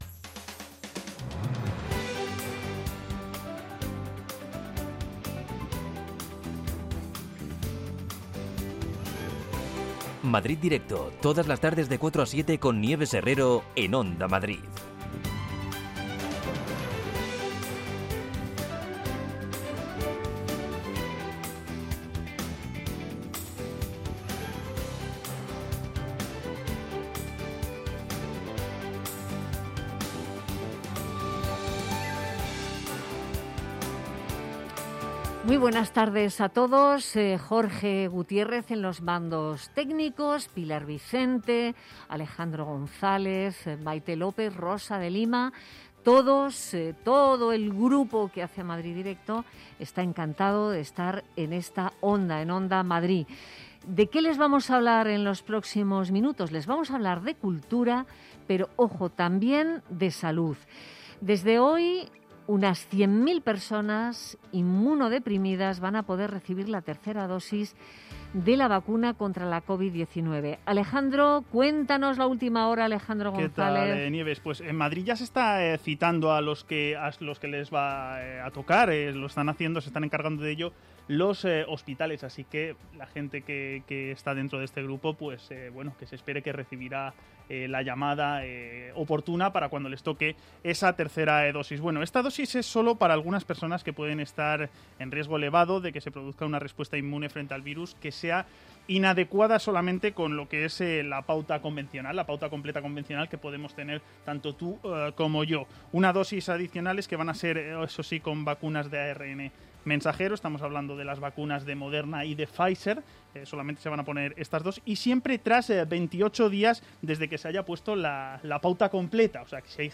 Nieves Herrero se pone al frente de un equipo de periodistas y colaboradores para tomarle el pulso a las tardes. Cuatro horas de radio donde todo tiene cabida.